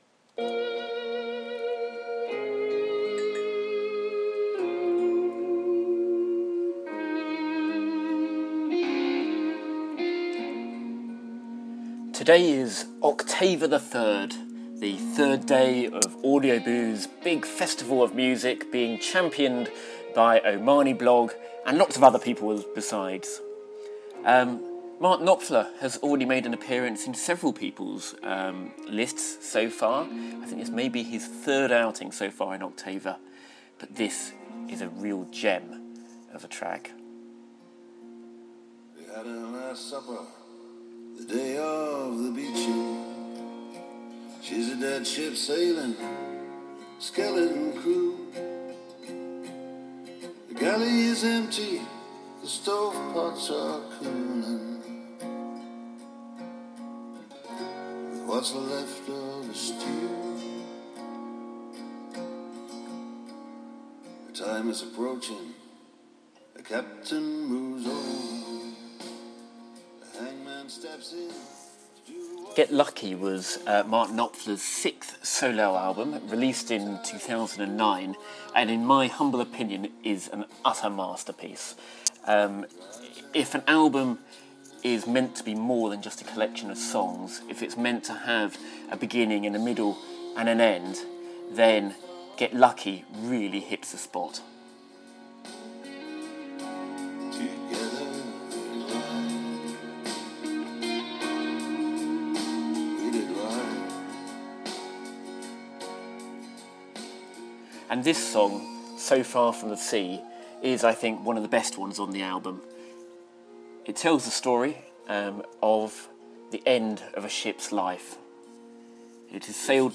heart rending ballad